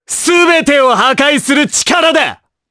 Dimael-Vox_Skill6_jp.wav